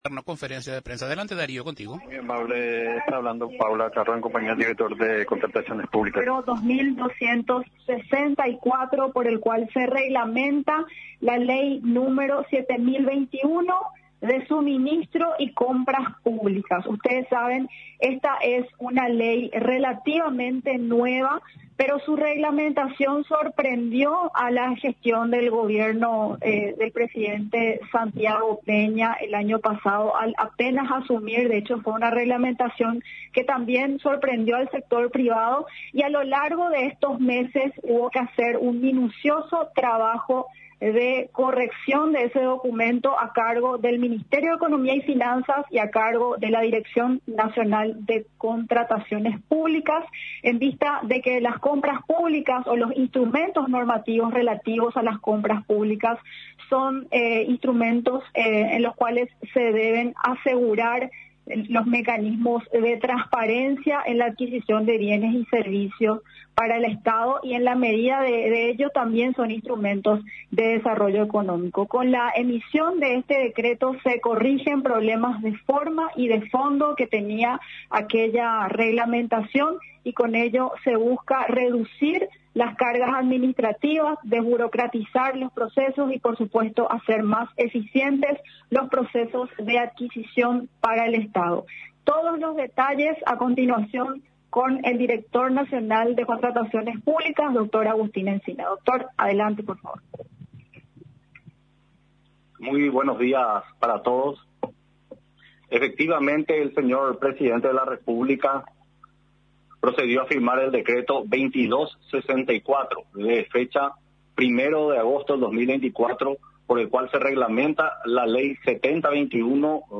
Durante la rueda de prensa, realizada en la Residencia Presidencial de Mburuvichá Róga, afirmó el nuevo decreto, cuenta con 237 artículos, introduciendo una serie de modificaciones y adiciones importantes con respecto al anterior Decreto N.º 9823/23 que contaba con 207 artículos.